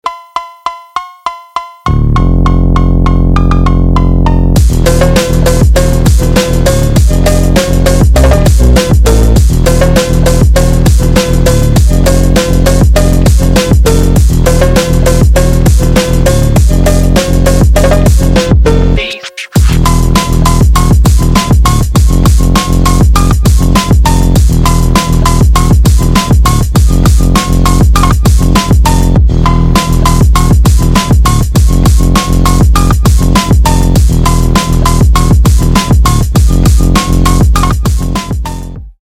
2025 » Новинки » Громкие » Без Слов » Фонк Скачать припев